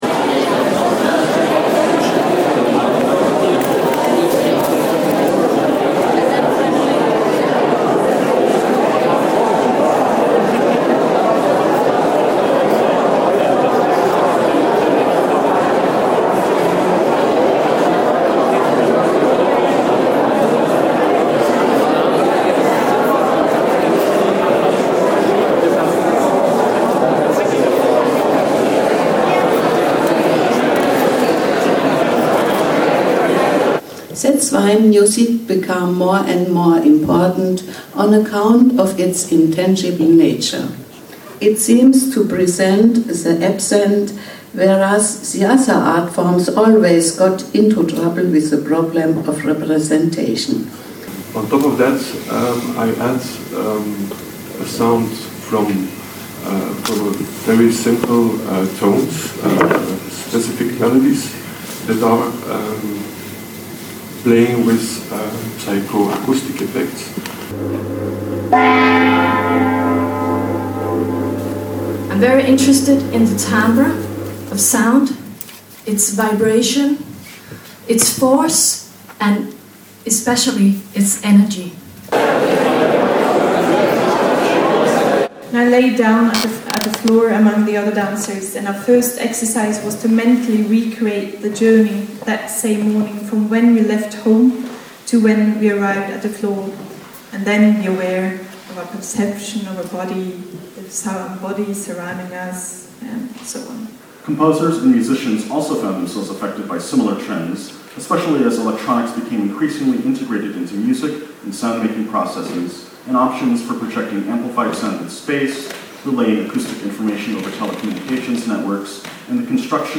Listen to a chronological collage of pseudo-random snippets of lo-fi audio, recorded during the first day of the Ephemeral Sustainability conference in Bergen: